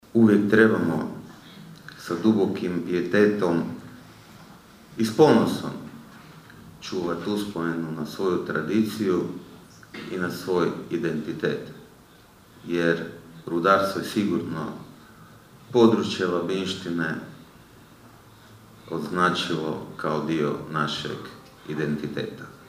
U Raši je održana komemoracija povodom 86. obljetnice rudarske tragedije iz 1940. godine u kojoj je poginulo 185 rudara. Održani su govori, misa i prigodni program.